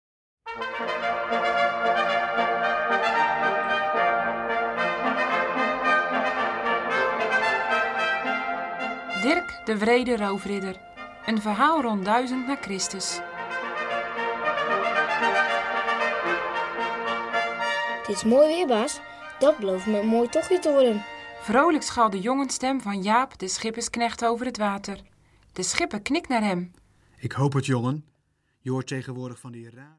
Vertellingen